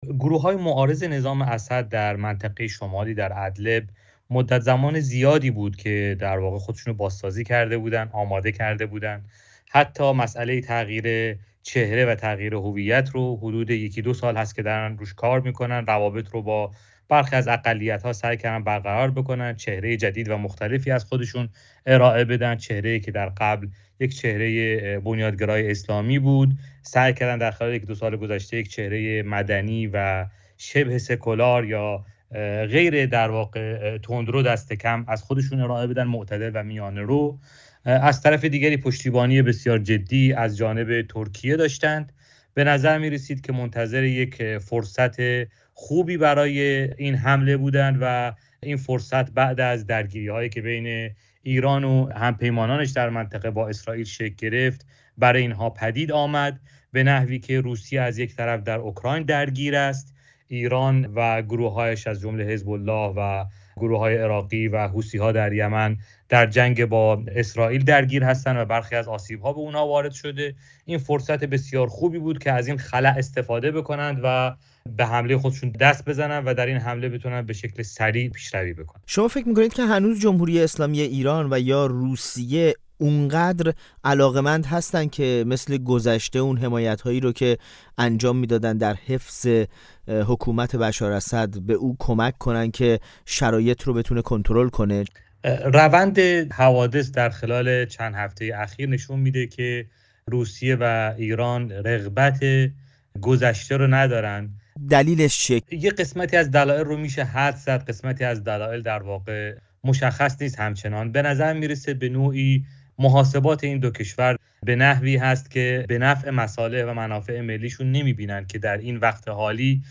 در گفت‌وگو با برنامه ساعت ۱۴رادیو فردا به این پرسش پاسخ می‌دهد که چرا حکومت اسد به سرعت فروپاشید؟